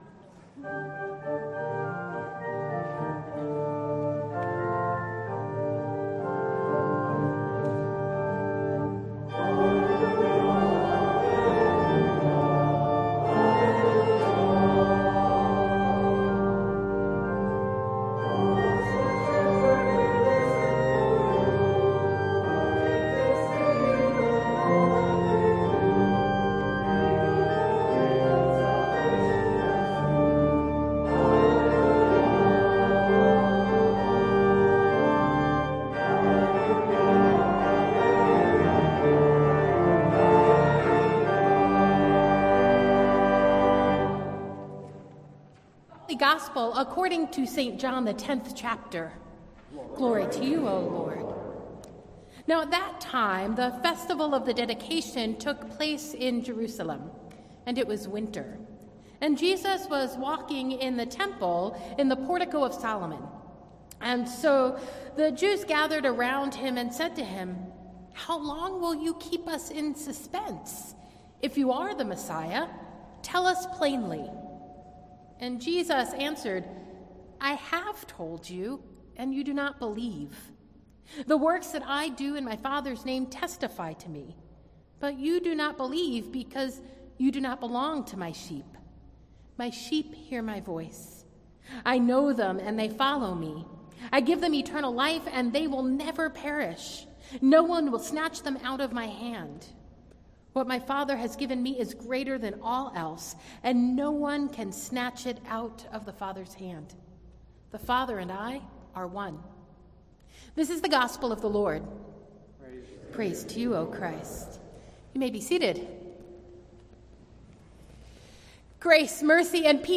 Sunday, May 8 Worship
Sermon Notes